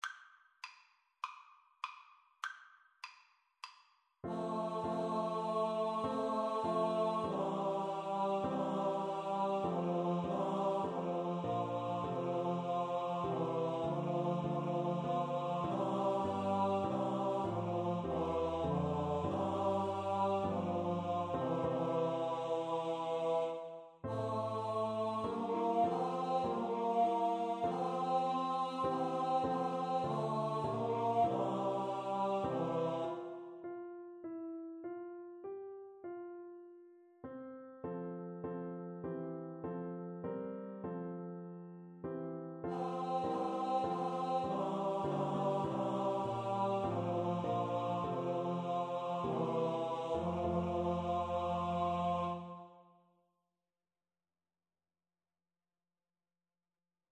F major (Sounding Pitch) (View more F major Music for Choir )
4/4 (View more 4/4 Music)
Traditional (View more Traditional Choir Music)
o_come_PNO_kar3.mp3